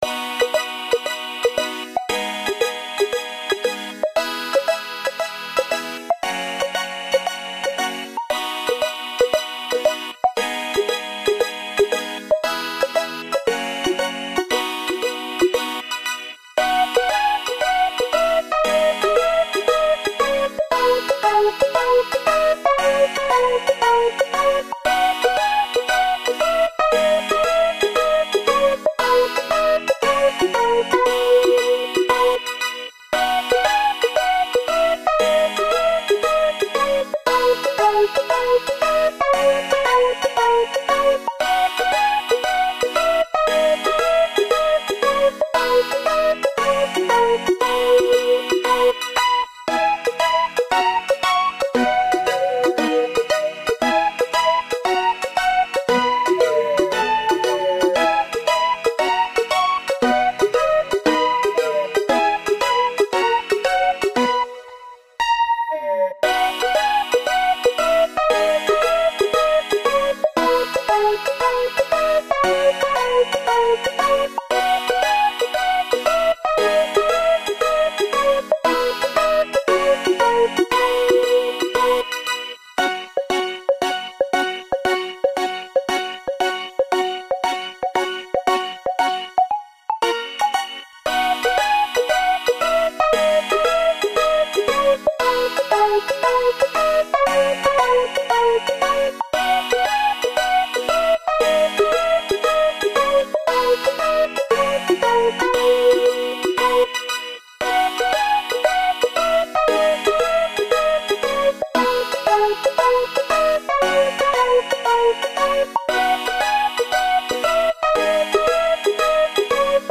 【用途/イメージ】春 陽気